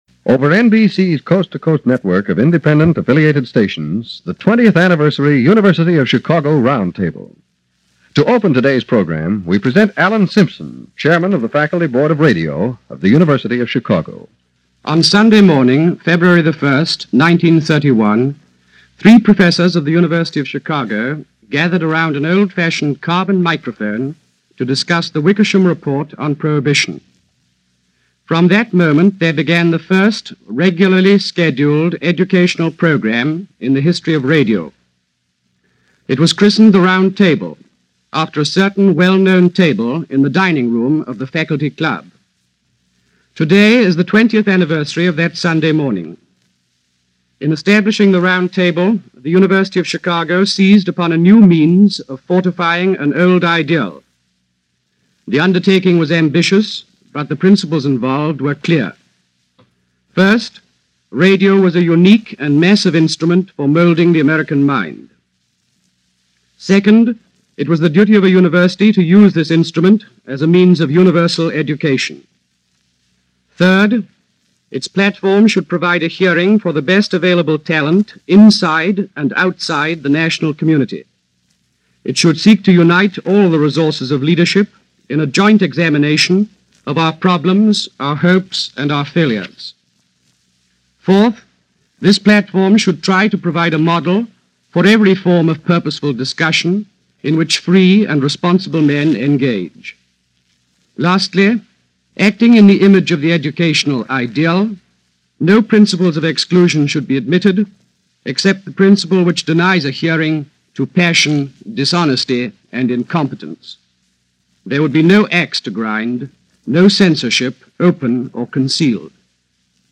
Red Scare: Individual Freedom And National Security - 1951 - Past Daily Reference Room - NBC Northwestern University Roundtable